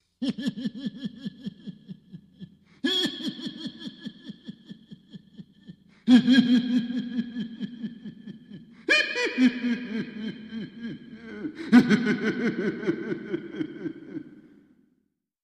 Laughter
Maniacal laughter, one man